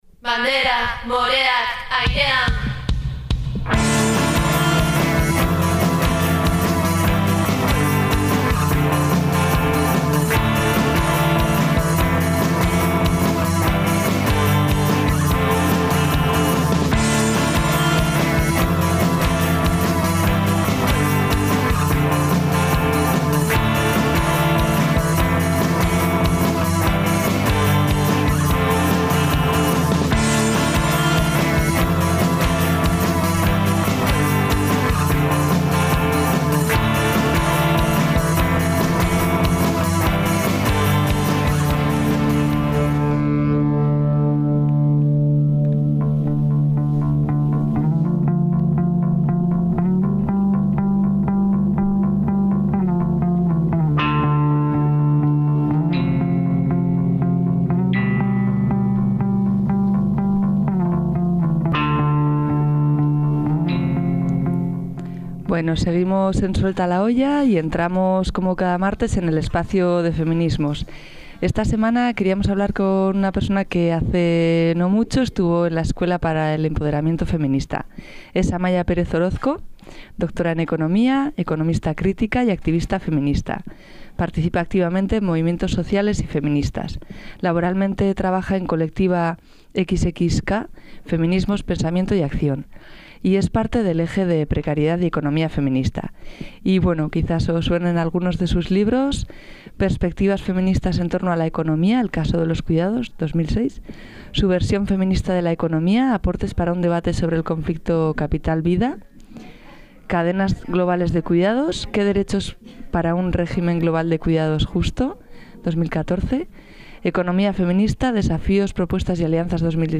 Han salido temas muy interesantes y debates que quedan por profundizar, pero queríamos señalar varias citas de la entrevista y animaros a escucharla: